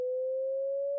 Still hear steps in engine sound, they're smaller but still very audible, specially in the XFG. Don't know if steps is the right word for it so i made examples, these are very exaggerated, LFS sound isn't this bad.
steps.mp3 - 10.4 KB - 453 views